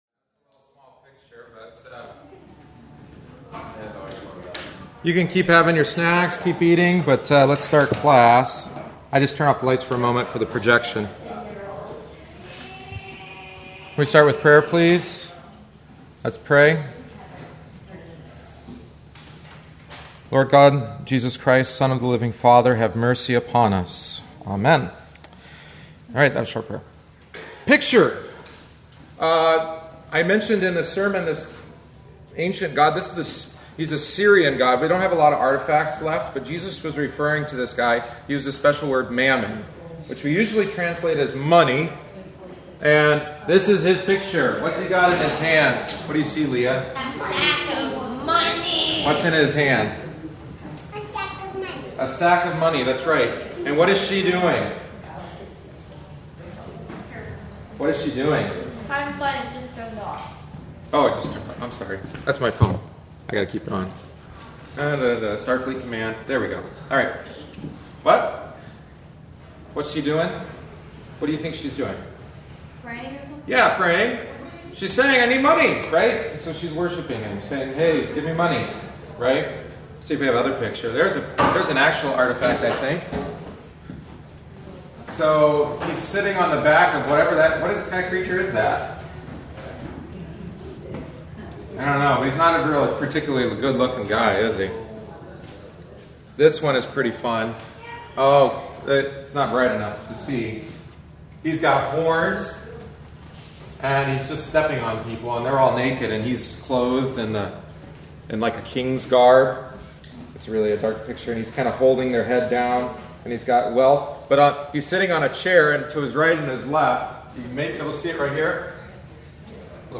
Join us each week after Divine Service (~10:15am) for coffee, treats, and a study of the Sunday Gospel text.